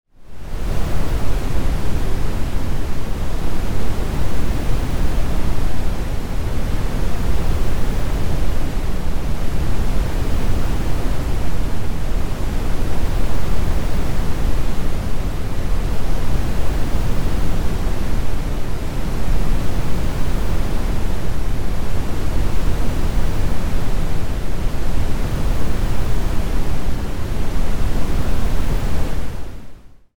Brain Wave Audio
Binaural noise that tickles the brain